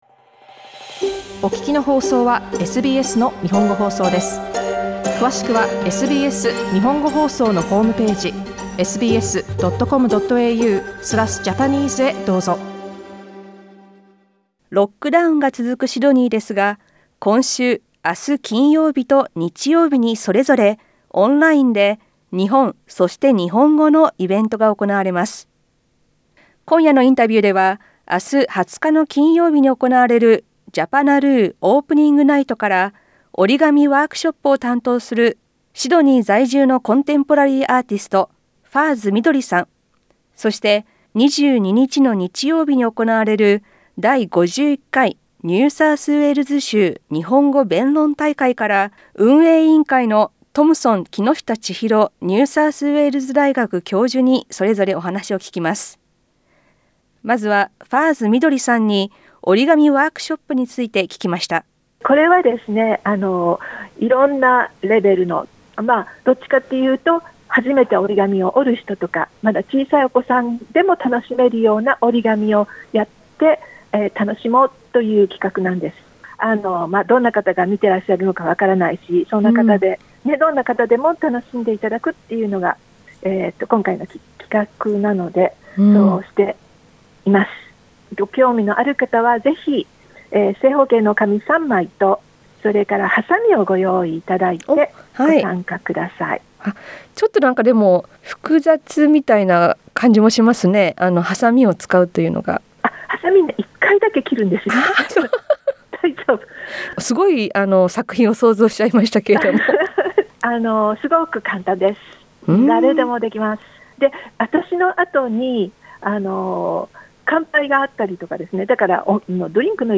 SBSの日本語放送